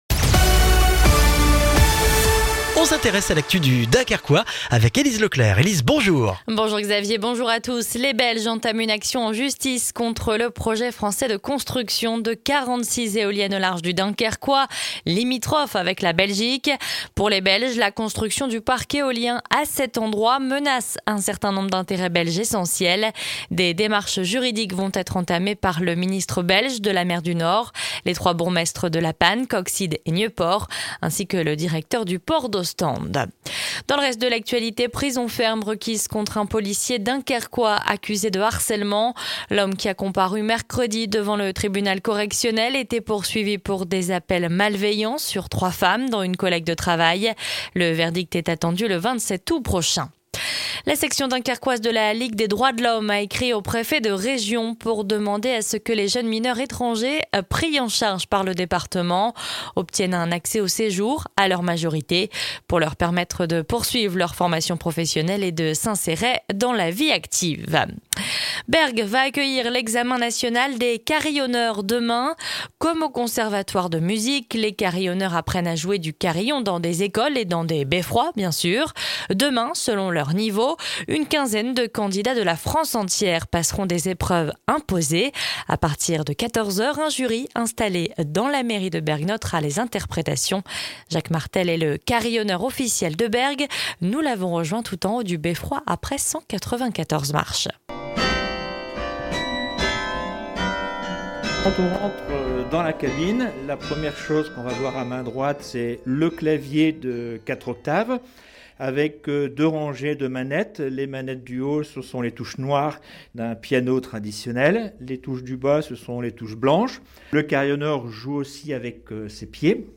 Le journal du vendredi 25 juin dans le Dunkerquois